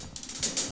27DR.BREAK.wav